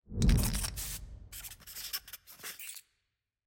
sfx-jfe-ui-logo.ogg